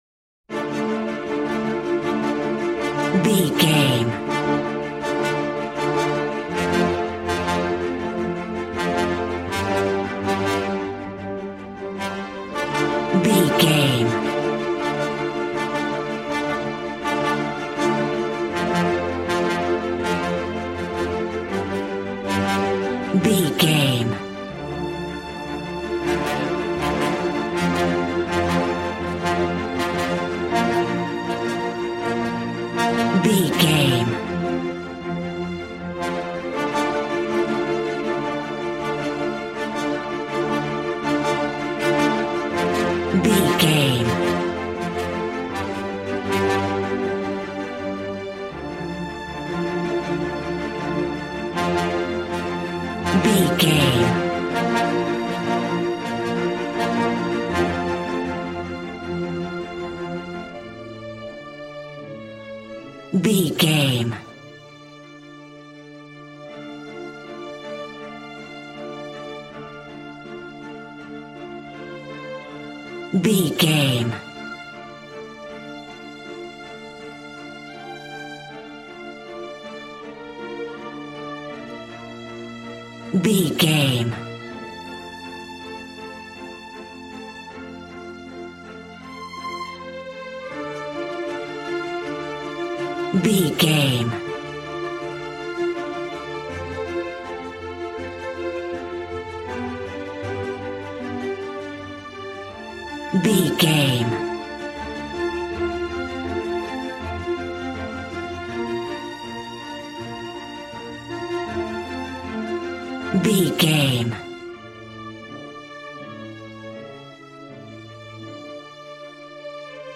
A classical music mood from the orchestra.
Regal and romantic, a classy piece of classical music.
Ionian/Major
regal
cello
violin
strings